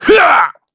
Shakmeh-Vox_Attack5_kr.wav